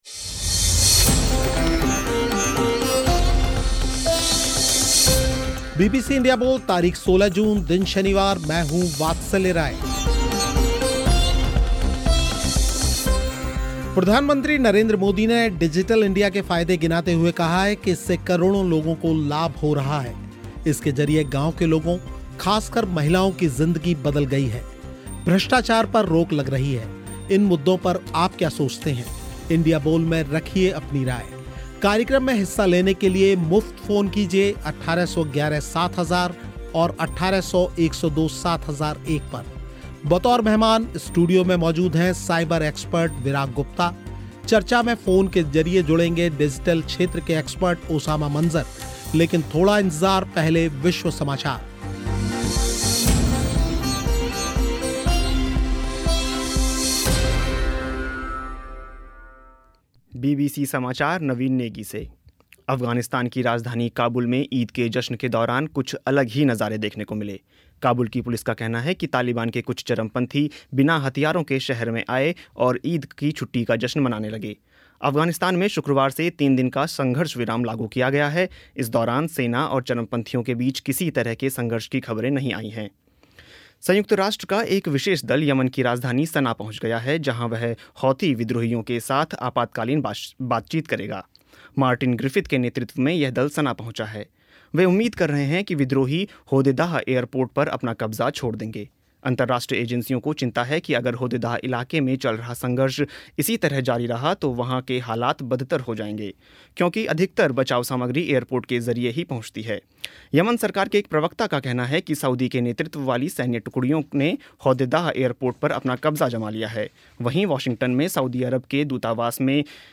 साथ में श्रोताओं ने भी रखी अपनी राय